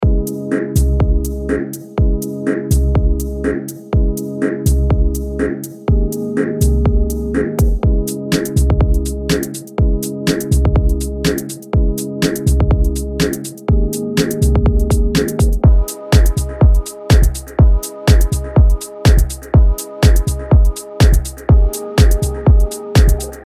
ダンスフロアに向けたパンチの効いた4つ打ちビートとグルーヴ
フロアを熱狂させる4つ打ちのキック、パンチのあるクラップ、シンコペーションの効いたハイハット、温かみを持つ質感のパーカッションで、あなたのトラックを躍動させましょう。
XO Expansion House プリセットデモ